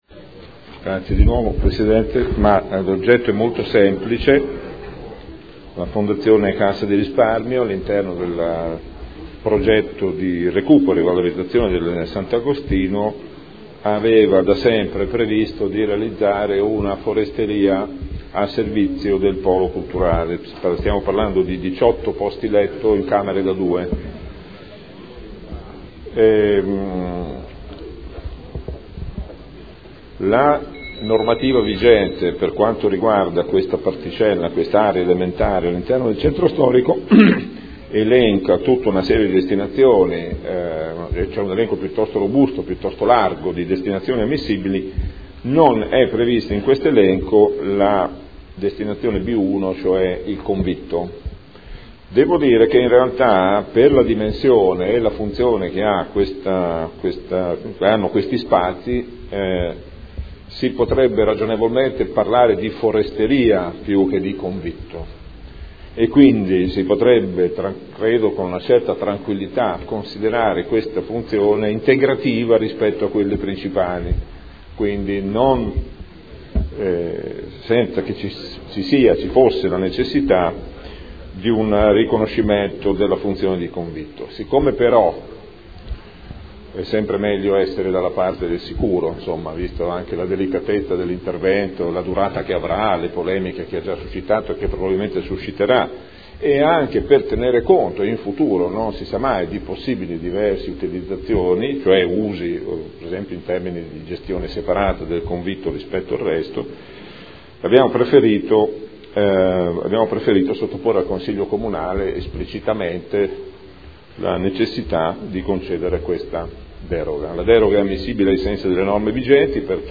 Gabriele Giacobazzi — Sito Audio Consiglio Comunale
Seduta del 9 gennaio. Proposta di deliberazione: Nulla osta al rilascio del permesso di costruire in deroga agli strumenti urbanistici presentato da Fondazione Cassa di Risparmio di Modena per realizzare un convitto all’interno del nuovo polo culturale nel complesso immobiliare Sant’Agostino (Ex Ospedale Civile)